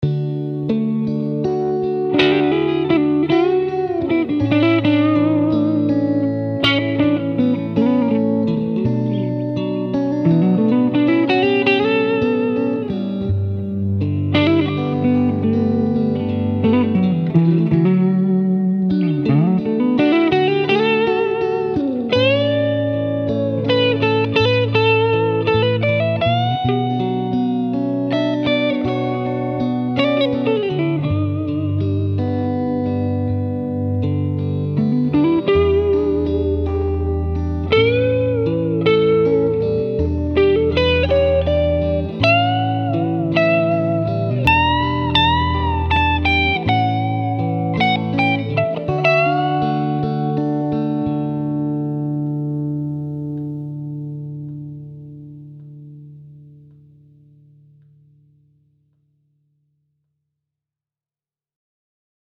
Featuring a solid mohagany body and neck with flame maple top and rosewood neck, the XT Sunburst has a naturally bright voice that's both versatile and expressive.